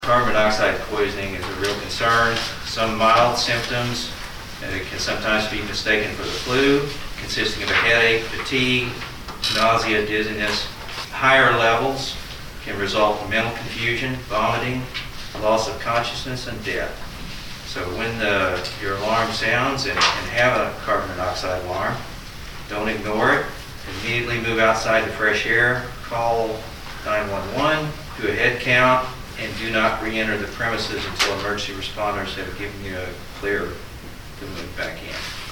Helpful hints and recommendations were made by Marshall City Councilmen during the council meeting on Monday, February 1.
As part of his monthly report from the fire department, Ward 2 Councilman Chuck Hines talked about what residents need to do should the alarm on their carbon-monoxide detector sound.